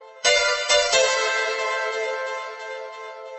SAMPLES : Piano
piano nē 33
piano33.mp3